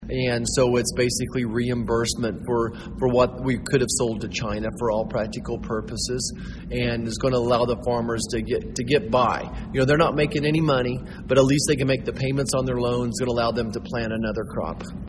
Touting a message of optimism for Kansas farmers, 1st District Congressman and current Republican Senate candidate Roger Marshall spoke to a gathering at the annual Young Farmers & Ranchers Leaders Conference Saturday at the Manhattan Conference Center.